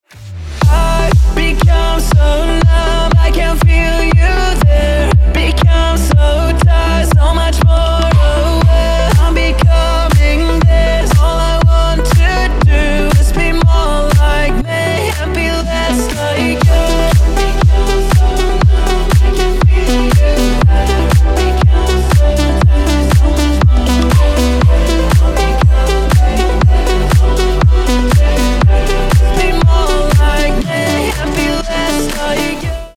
• Качество: 320, Stereo
мужской голос
deep house
Cover